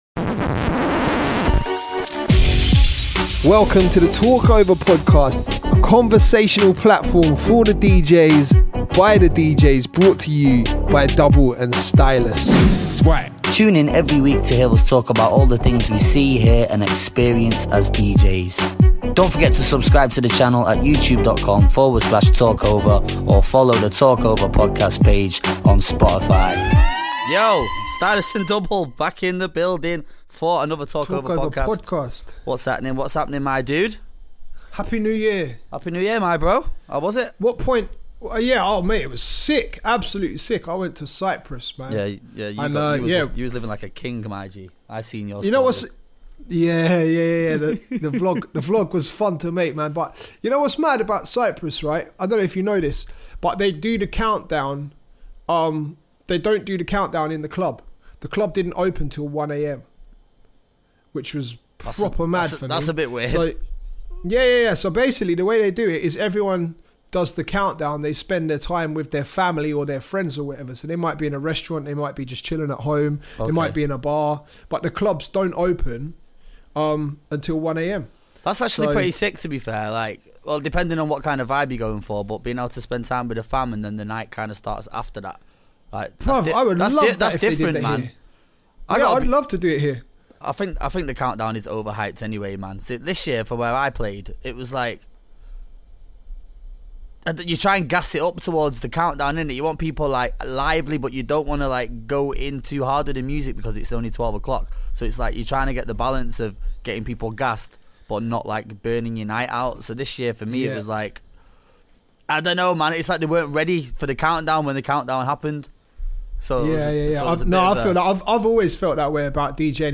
We discuss relevant topics, giving our unfiltered & honest opinions about anything that comes up in conversation!